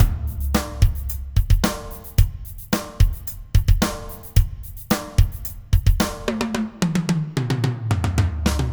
ax[0].set_title('drums signal')
80spopDrums.wav